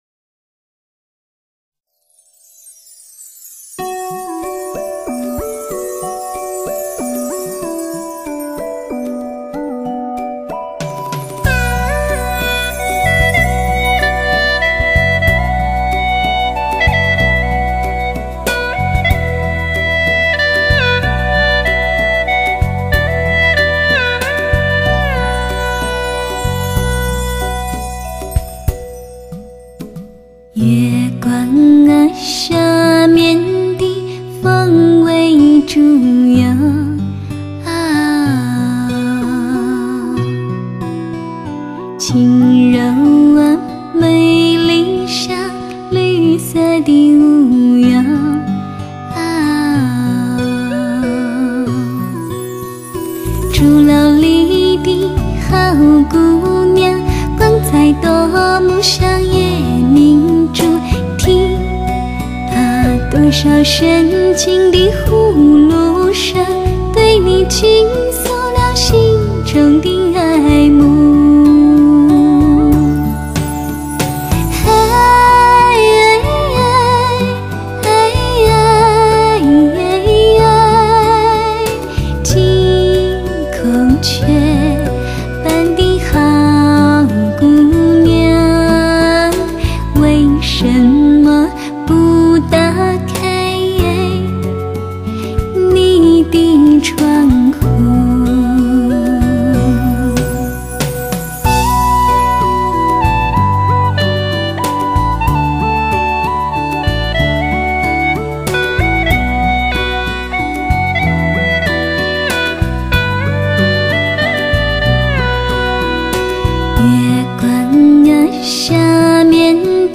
流动着自然音韵的纯净，梦吟着人间天堂的神奇。
葫芦丝音乐就是好听，歌声也不错